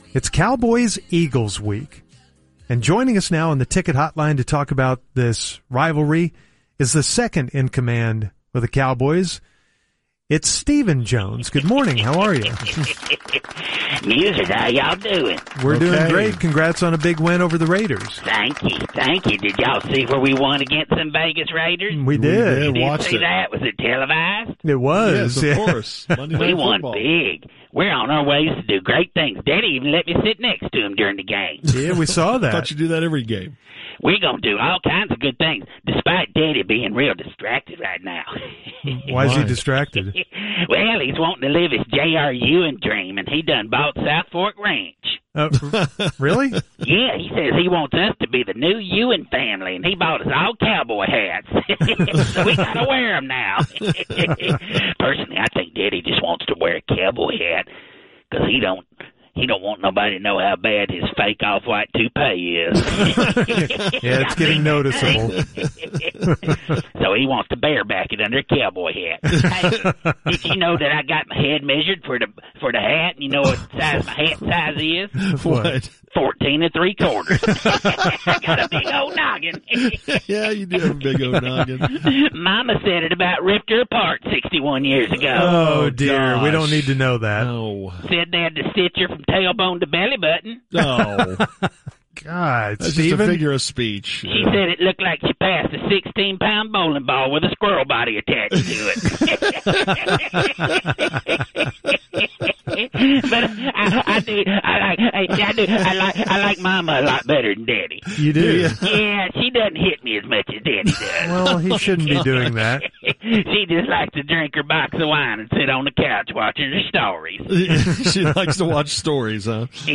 Fake Stephen Jones & Nico Harrison – The Musers 11.20.2025